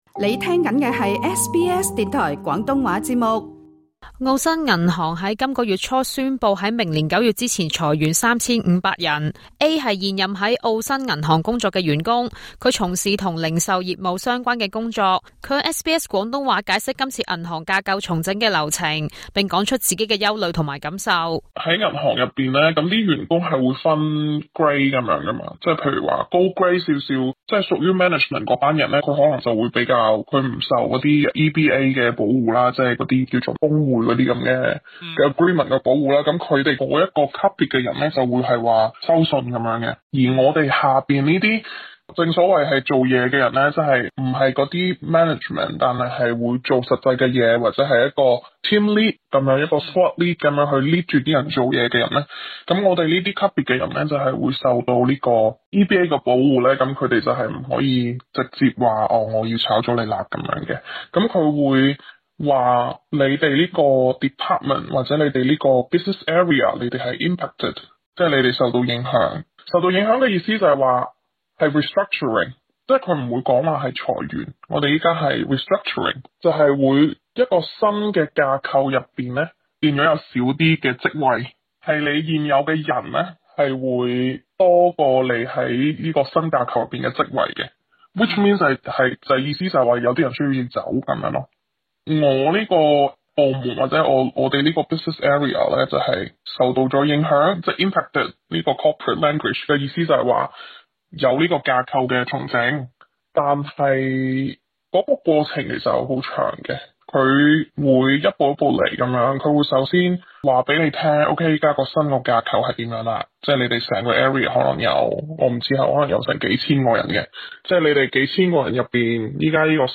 由於要保護受訪者身份，聲音經過特別處理。